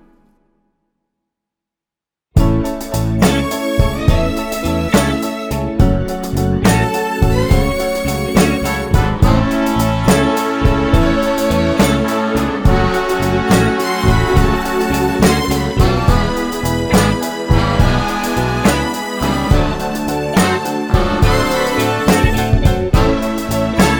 no Backing Vocals Jazz / Swing 2:55 Buy £1.50